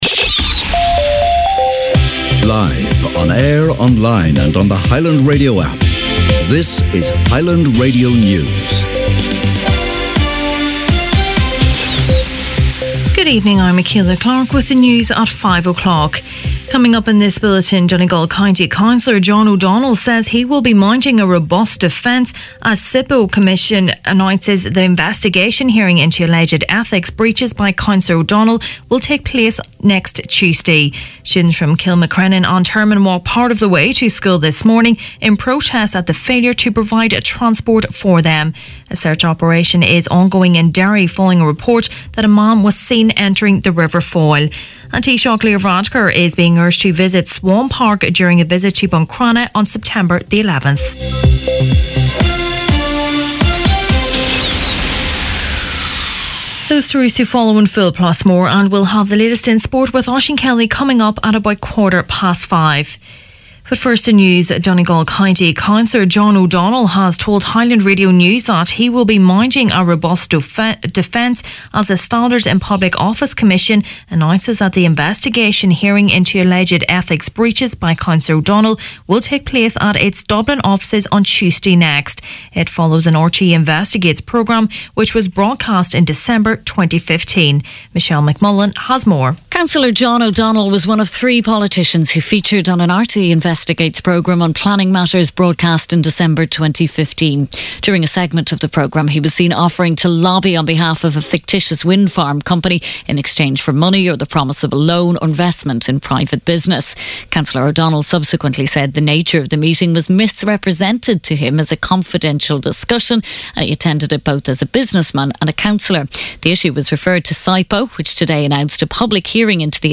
Main Evening News, Sport and Obituaries Monday September 3rd